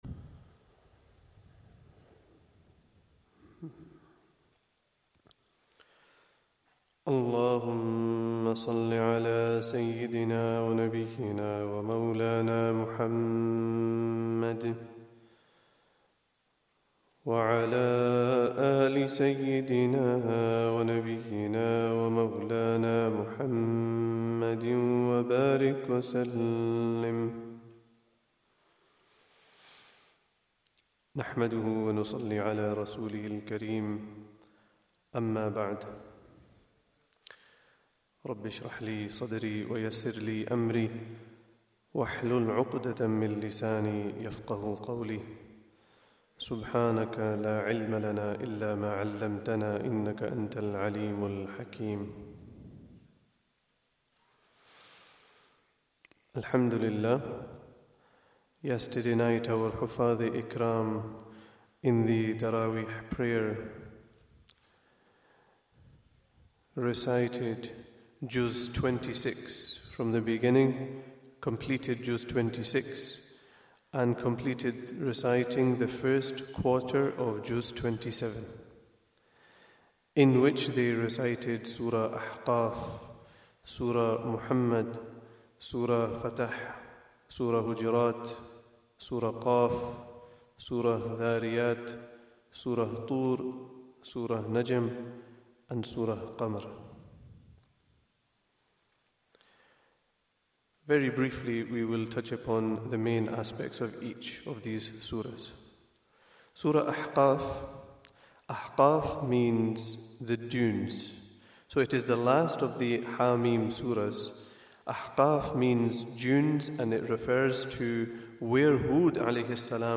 Day 21 - Taraweeh 1444 - Brief Explanation